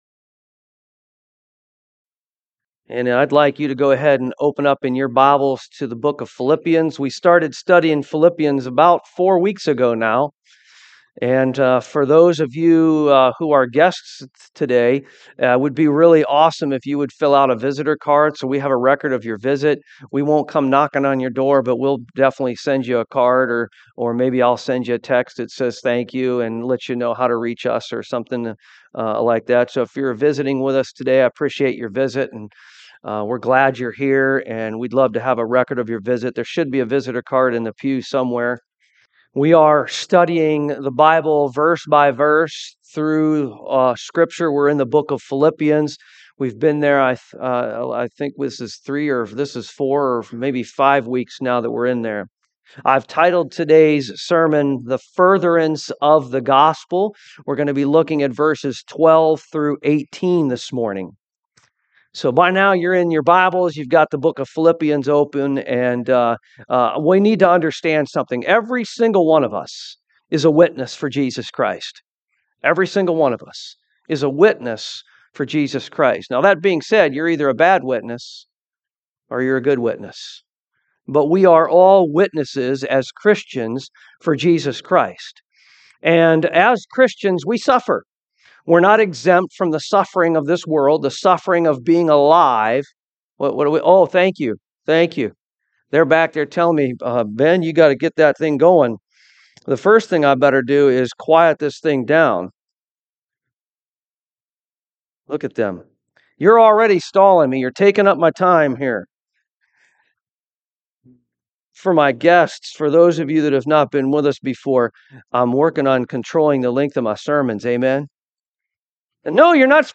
Philippians 1:12-18 Service Type: AM Where have you been led to witness because of hardship God allowed in your life?